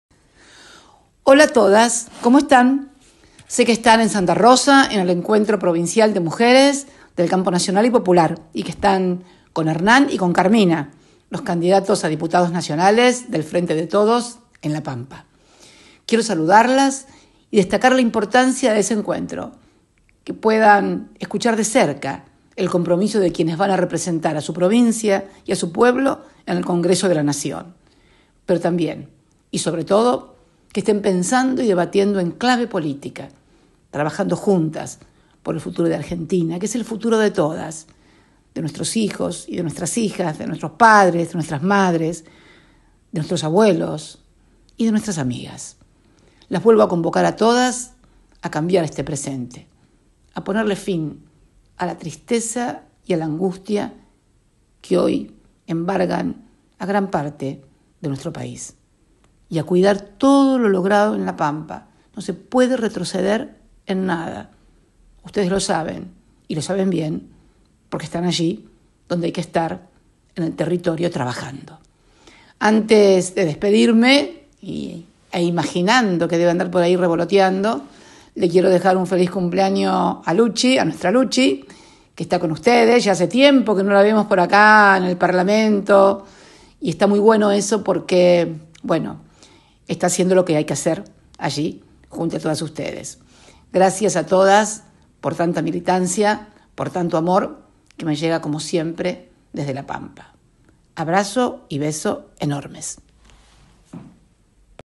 En el dia de ayer, se realizó un encuentro de mujeres en el Centro Empleados de Comercio, de la Capital pampeana.
“Gracias a todas por tanta militancia, por tanto amor que me llega como siempre desde La Pampa”, cerró el mensaje la expresidenta Cristina Fernández.
cristina_mensaje.mp3